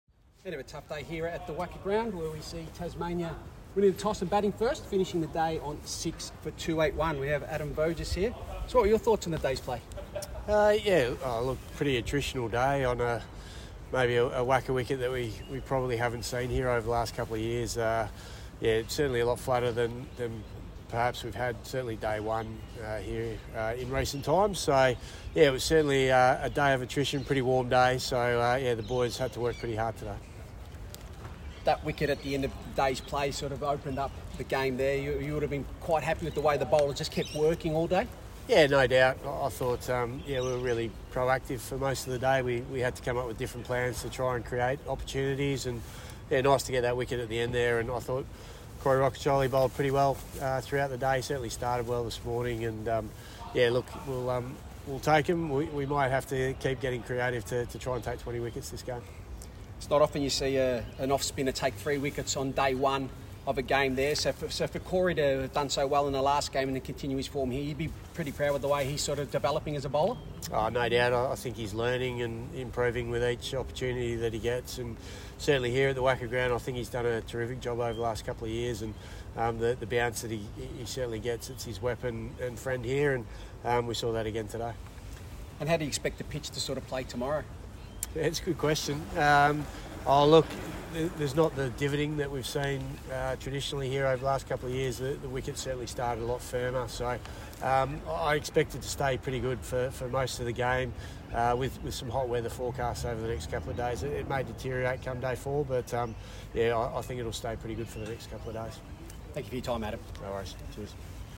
Western Australia Head Coach Adam Voges, post match interview, Western Australia vs Tasmania, 6th Match, Sheffield Shield